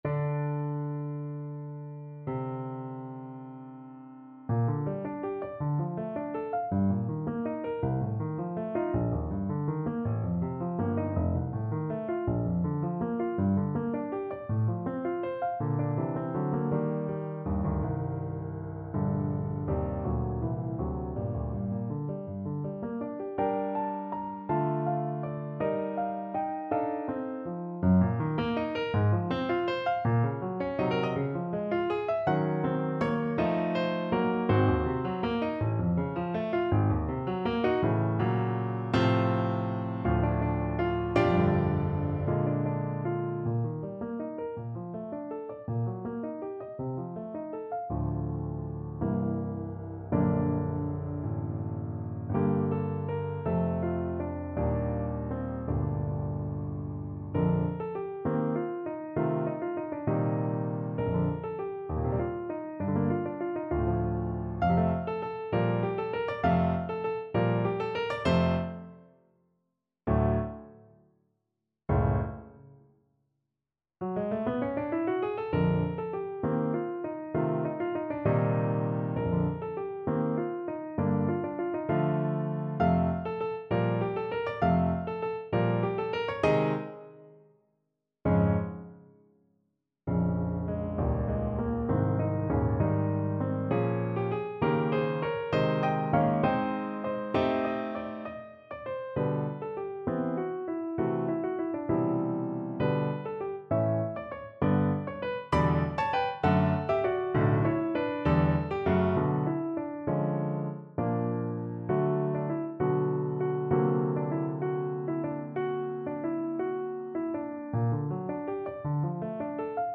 6/8 (View more 6/8 Music)
Classical (View more Classical Clarinet Music)